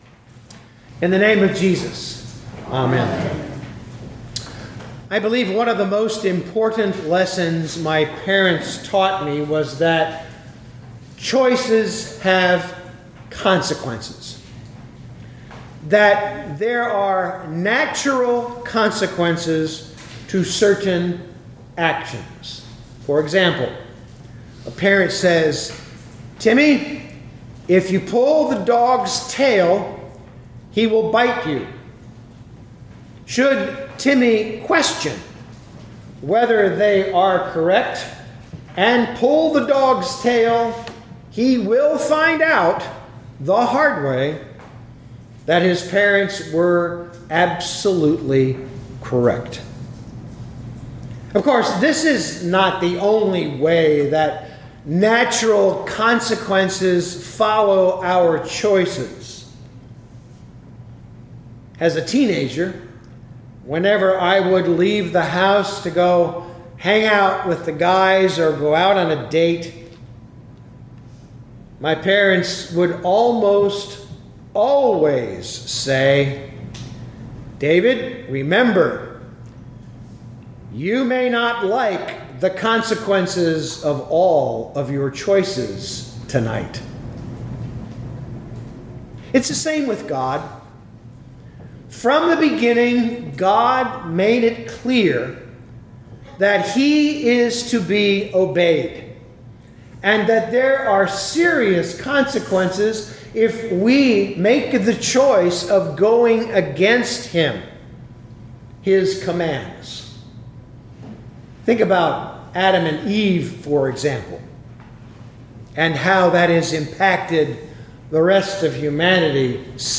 2024 Isaiah 35:4-7a Listen to the sermon with the player below, or, download the audio.